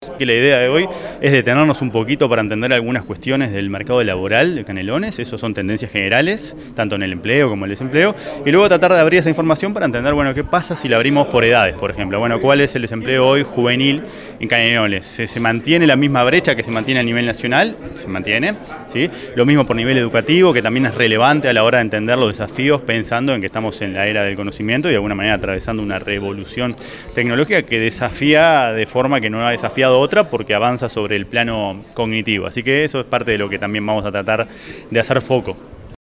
Charla sobre coyuntura económica nacional e internacional en Pando